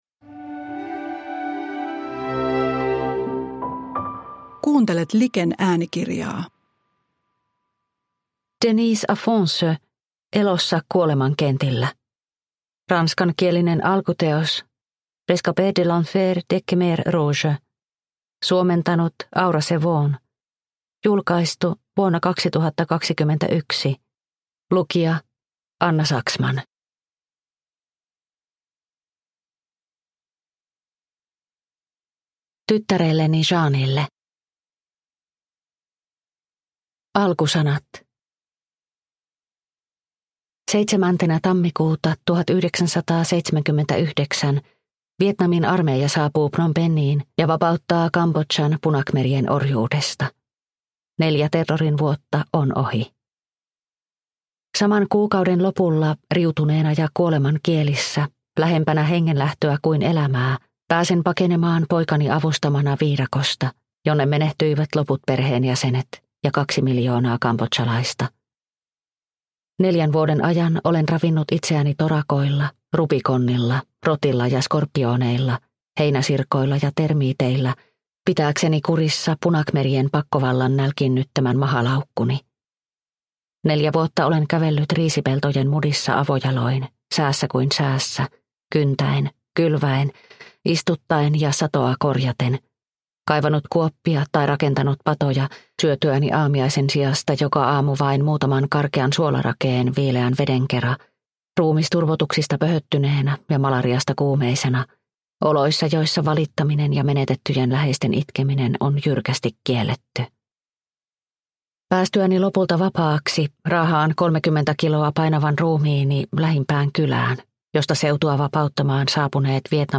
Elossa kuoleman kentillä – Ljudbok – Laddas ner